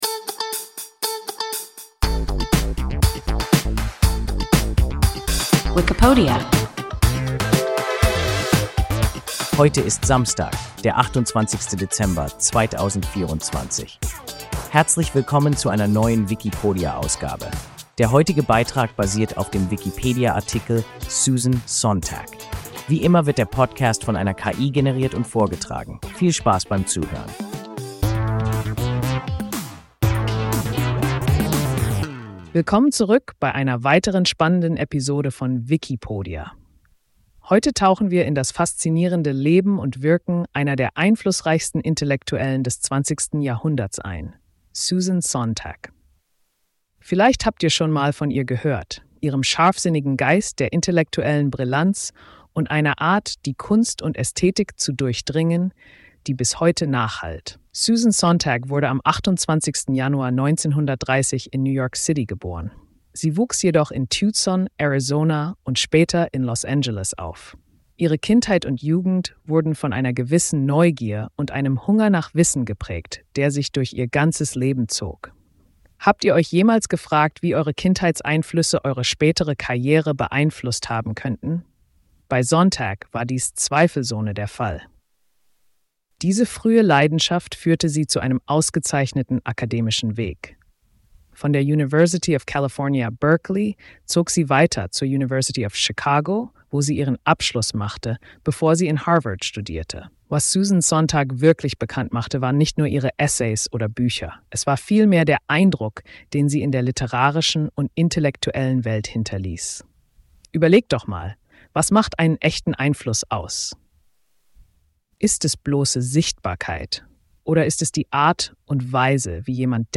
Susan Sontag – WIKIPODIA – ein KI Podcast